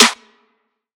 Snare [ Trapper ].wav